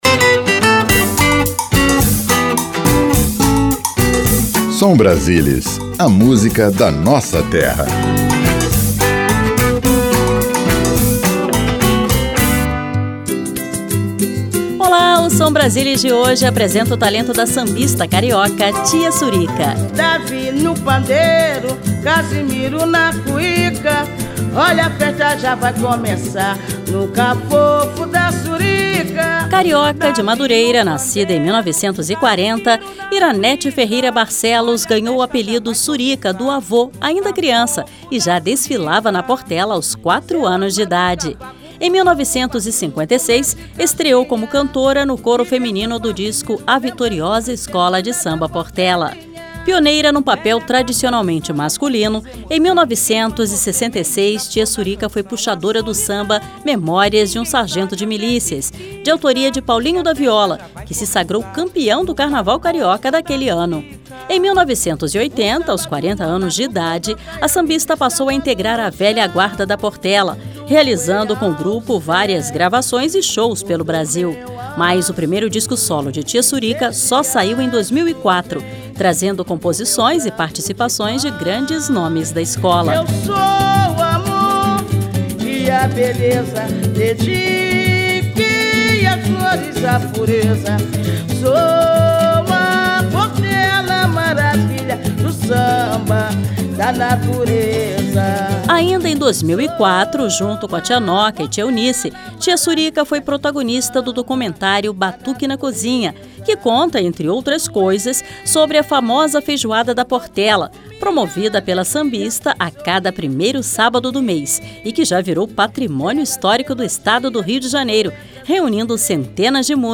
Samba
Ao vivo